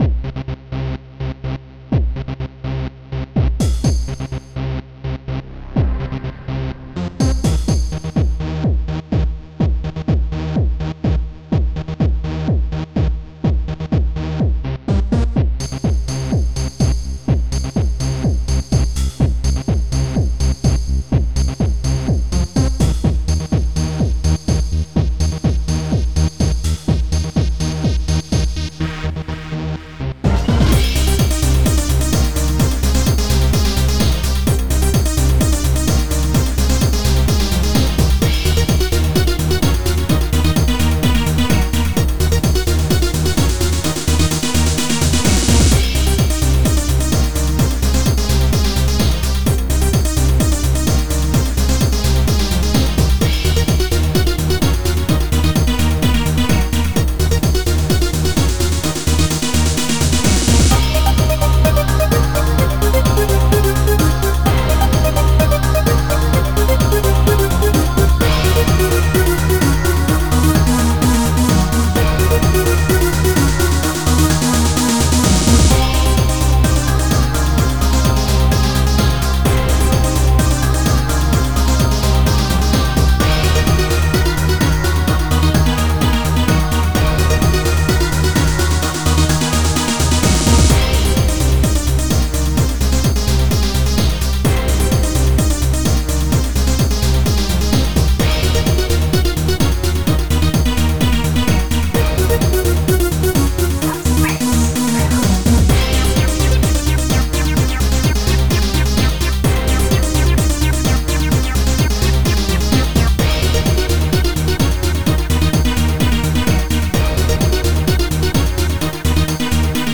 Impulse Tracker Module  |  1998-02-27  |  455KB  |  2 channels  |  44,100 sample rate  |  3 minutes, 30 seconds